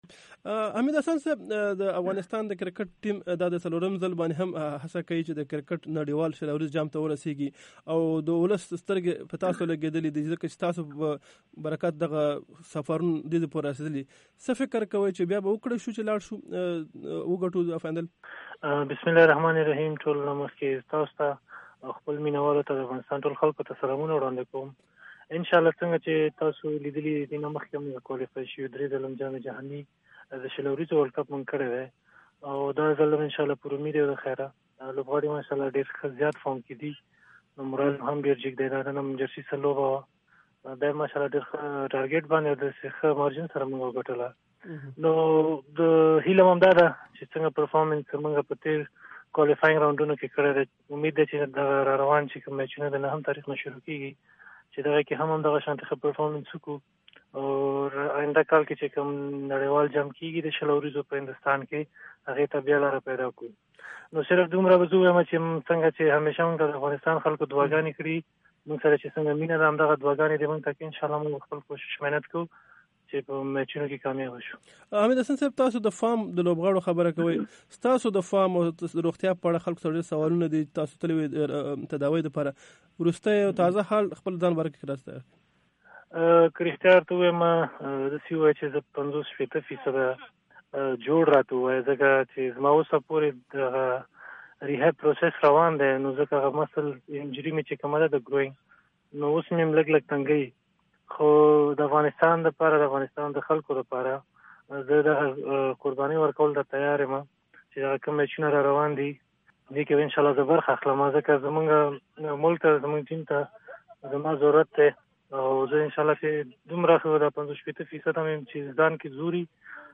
cricket Hamid Hassan interivew